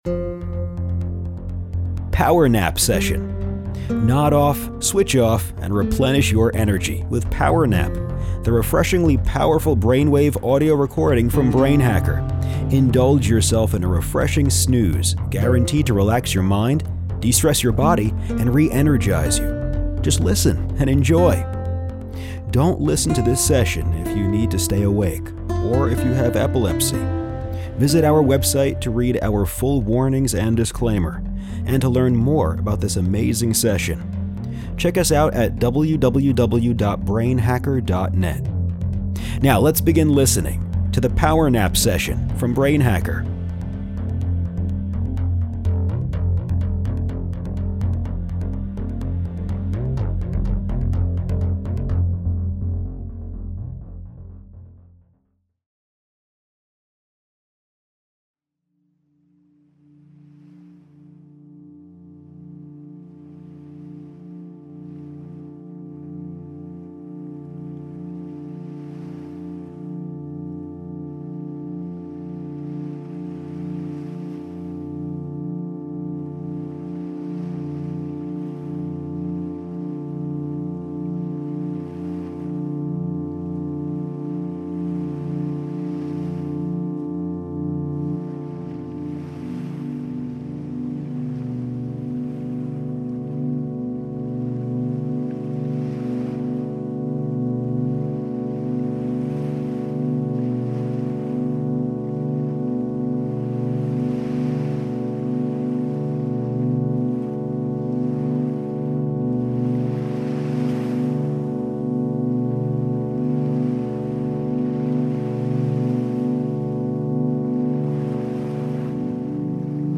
Používa isochronické tóny, ktoré nám pomôžu relaxovať a energetizovať sa. Synchronizuje mozog na frekvenciu 10 Hz, ktorá nám pomôže krátkodobo si osviežujúco zdriemnuť (=hodiť si šlofíka 😉 ).
Začína necelým minútovým hovoreným anglickým úvodom s inštrukciou, ktorá je napísaná už tu.